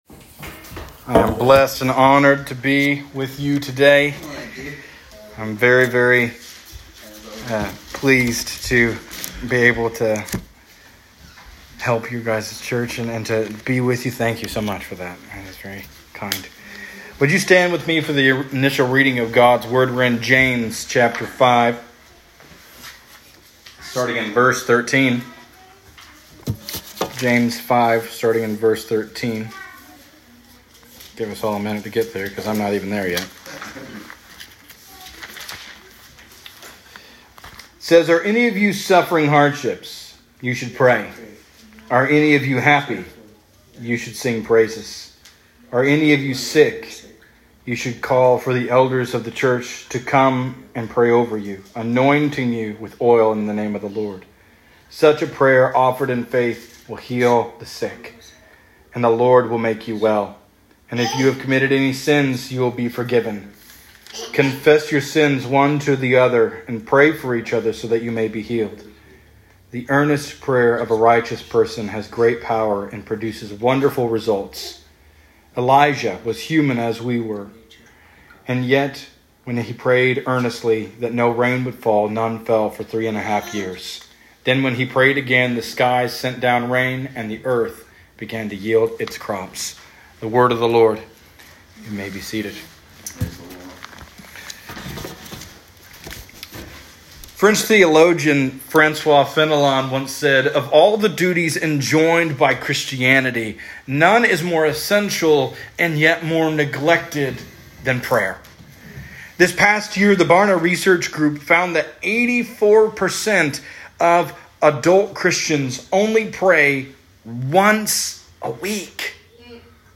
Sunday Service – January 19, 2025
Sunday morning service at Discover Church in Fishers, Indiana on January 19th, 2025. Sermon title – Sweet Hour of Prayer: